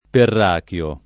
[ perr # k L o ]